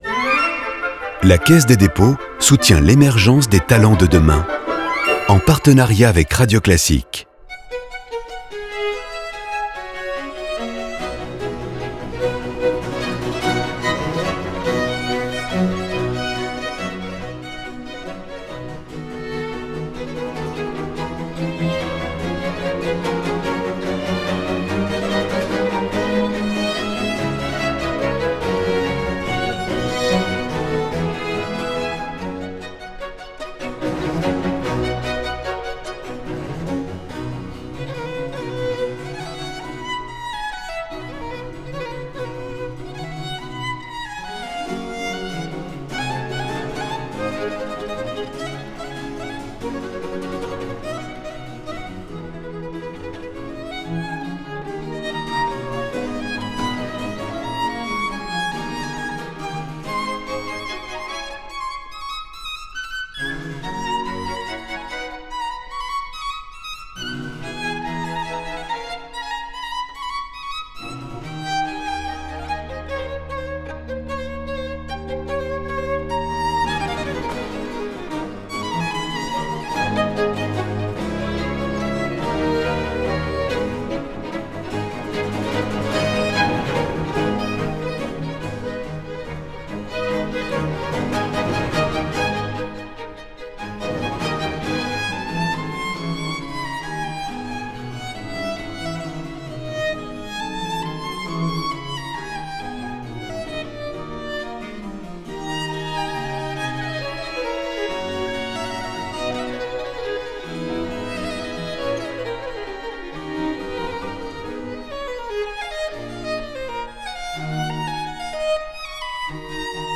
Concerto pour violon & cordes
ensemble indépendant de musique baroque.